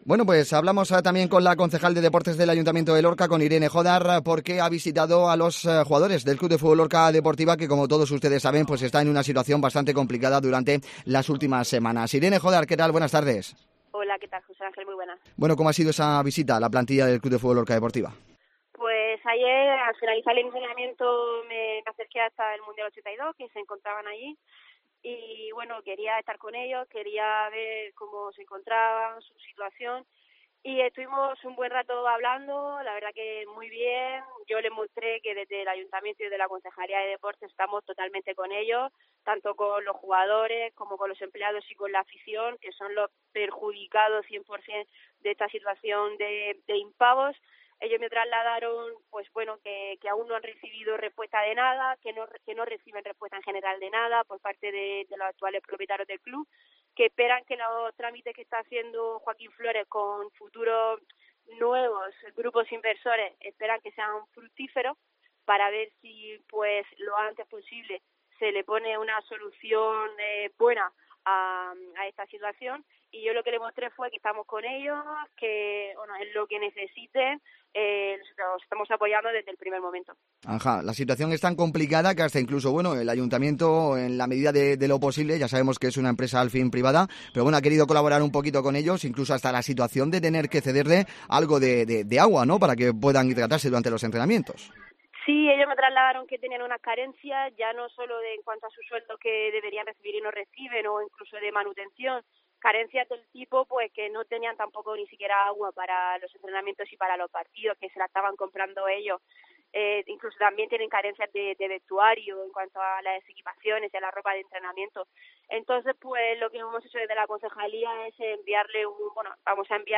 Irene Jódar edil del Ayuntamiento de Lorca en DXT Cope